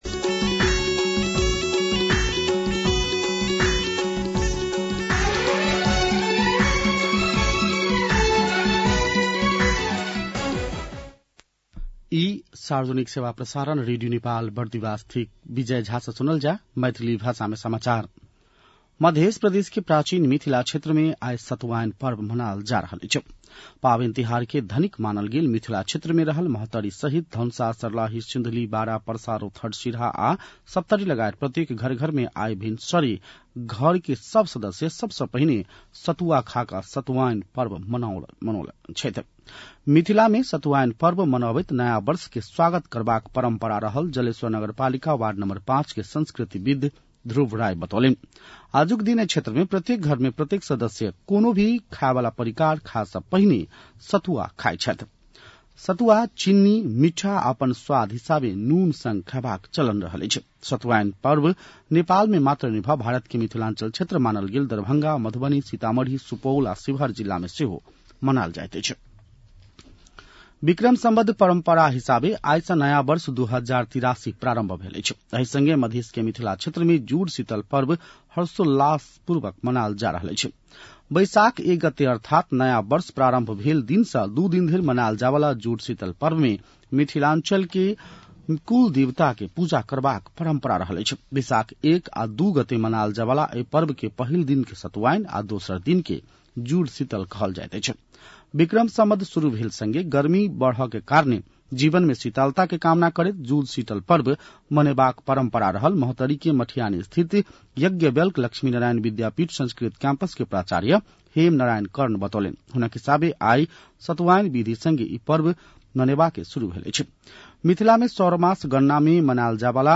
मैथिली भाषामा समाचार : १ वैशाख , २०८३